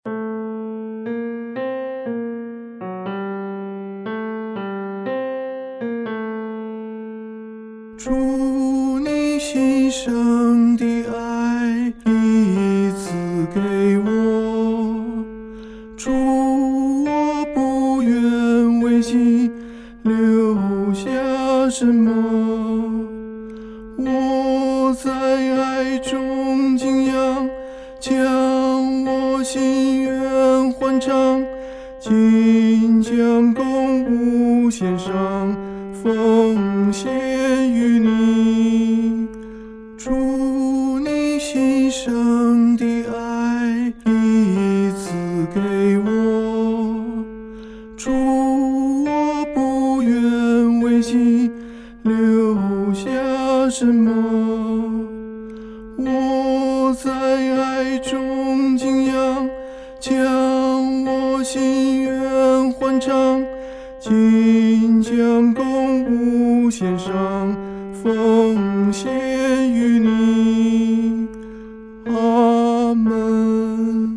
合唱
男高